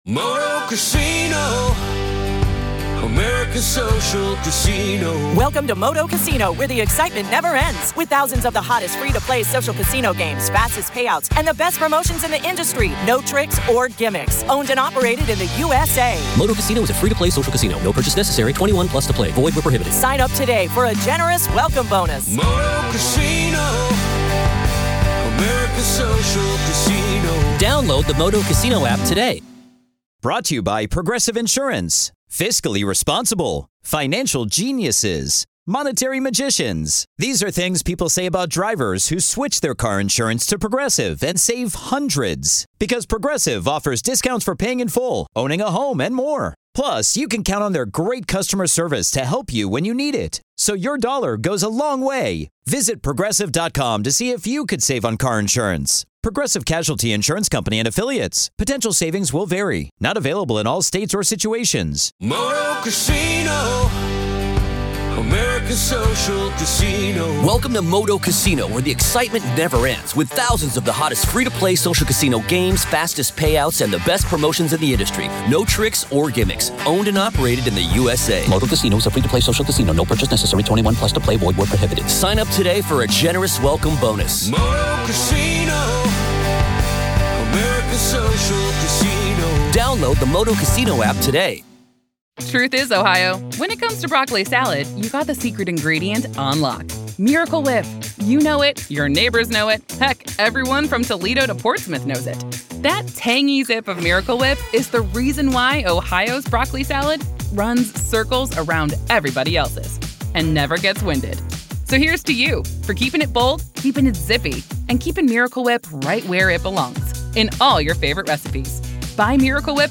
FOOTSTEPS in an Empty House + Tom Petty’s GHOSTLY Goodbye?! | Real Ghost Stories LIVE!